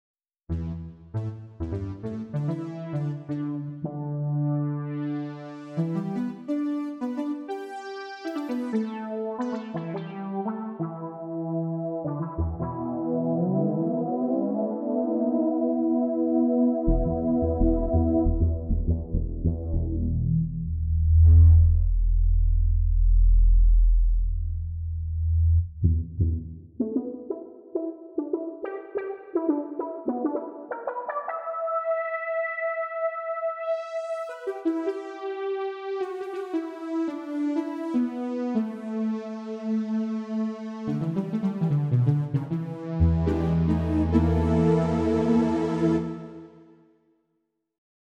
Within a week I have made these sounds, and recordings of them, using 24 bit 44.1kHz TOS link:
synthsupermoogtv.mp3